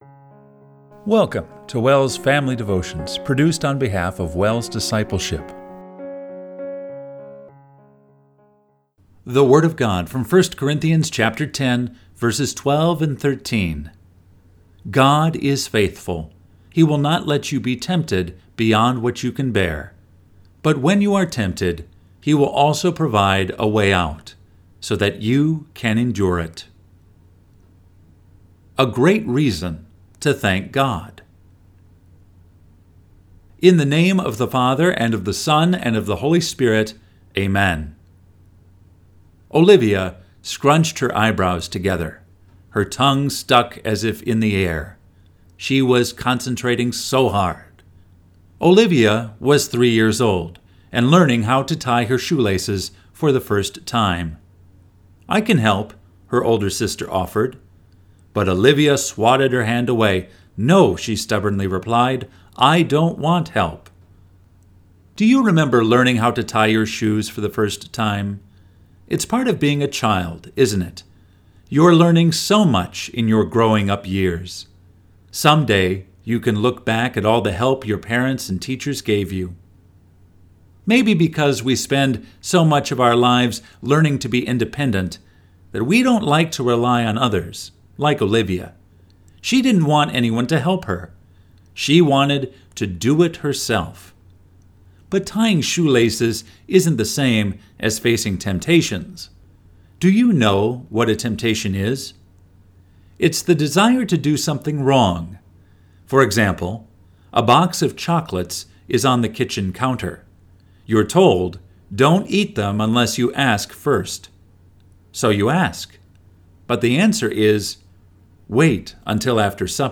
Family Devotion – August 14, 2024